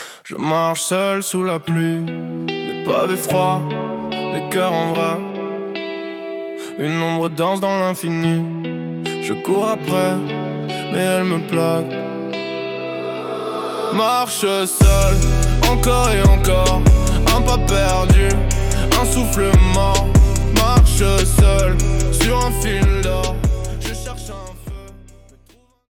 Style : Rap